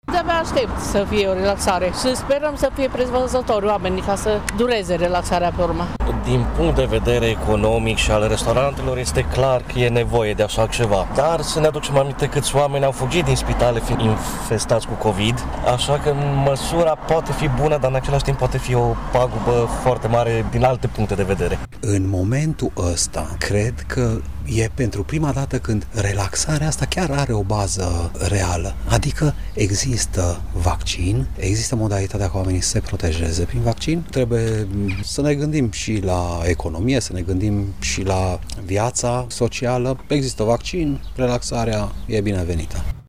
Mureșenii sunt în favoarea eliminării unor restricții, dar sunt încă precauți: